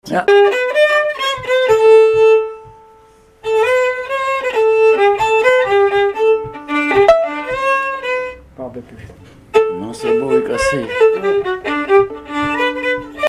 Pièces instrumentales à plusieurs violons
Pièce musicale inédite